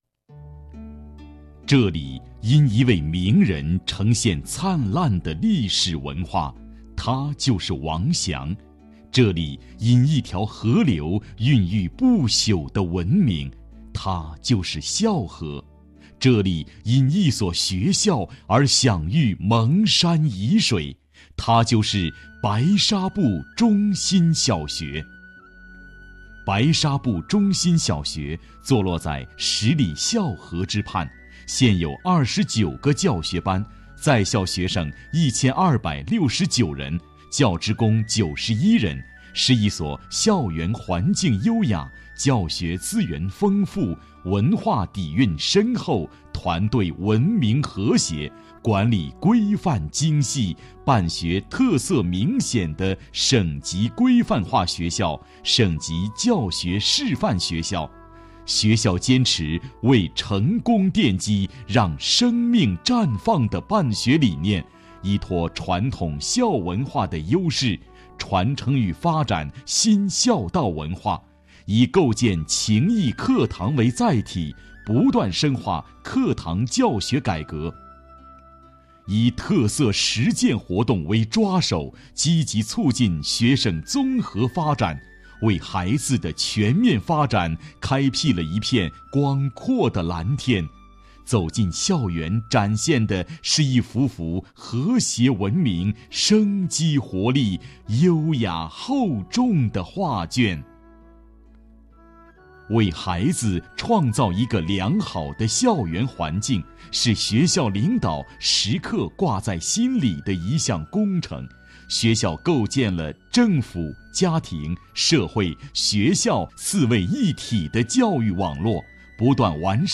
男49号-物理课件-探究平面镜成像时像距和物距的关系 男49年轻老师